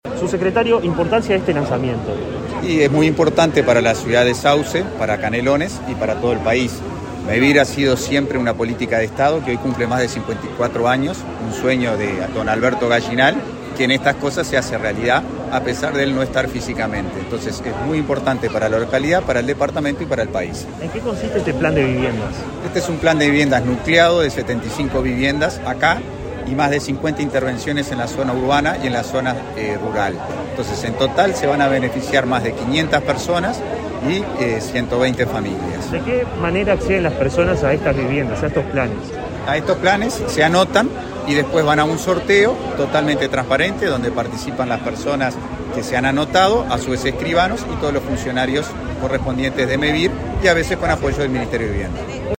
Palabras del subsecretario de Vivienda, Tabaré Hackenbruch